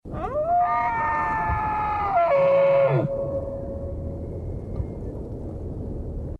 Звуки марала
Вы услышите характерный рев самцов во время гона, тревожные сигналы стада, шаги по снегу и другие природные звуки.
В лесу, в отдалении